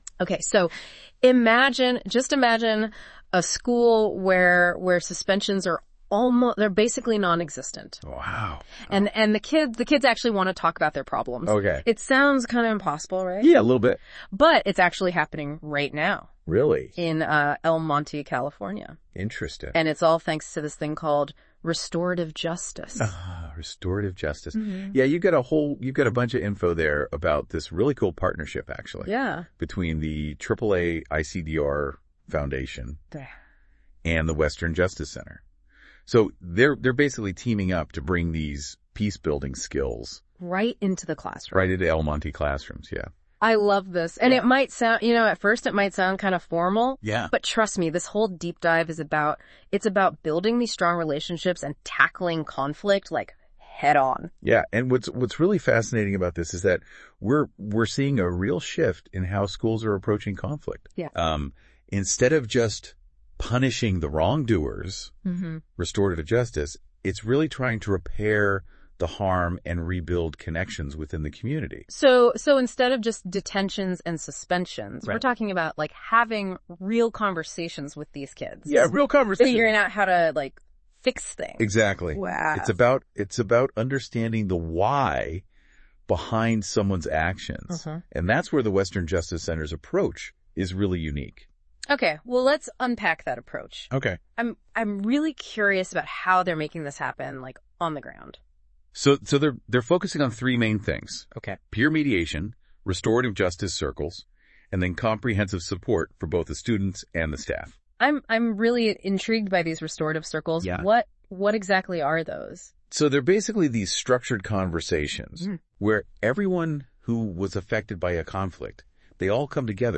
Experience this article as a podcast episode with Google NotebookLM’s innovative Audio Overview feature. This tool transforms written content into dynamic audio discussions, where AI hosts summarize key insights, link related topics and create an engaging conversation.
Click here to listen to the AI-generated podcast summary of this article